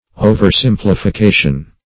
oversimplification.mp3